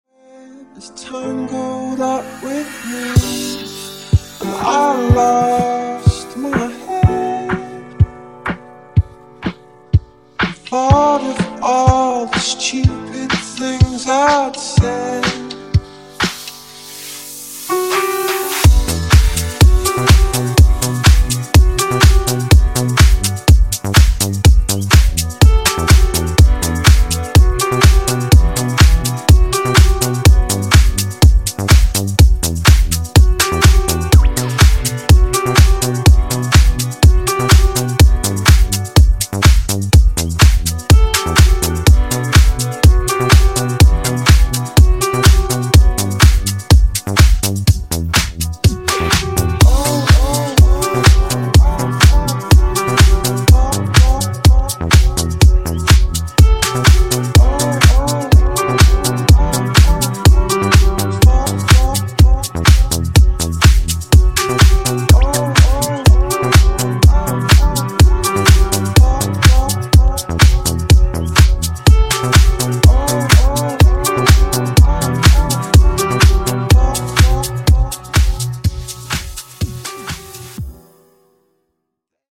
Genres: DANCE , RE-DRUM , TOP40